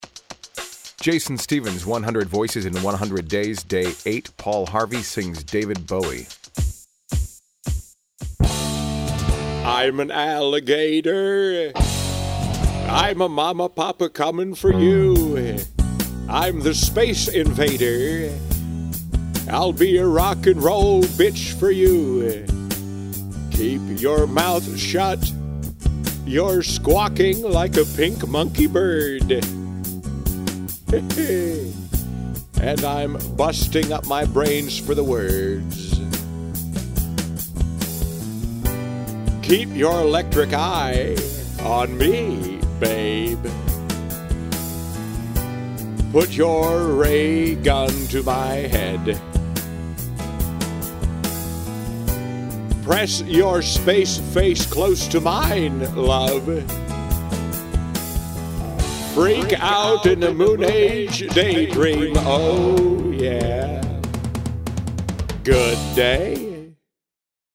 Tags: Paul Harvey impression